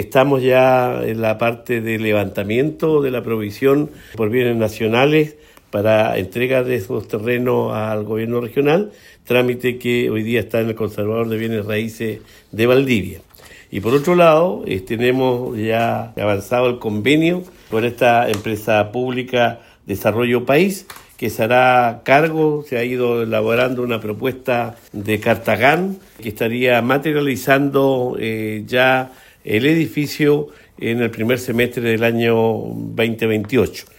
Tras ser consultados por Radio Bío Bío, el gobernador Regional de Los Ríos, Luis Cuvertino, confirmó que están terminando el proceso administrativo – técnico para poder aplicar la carta Gantt, que es la hoja de ruta que permite ver el cronograma de todo proyecto.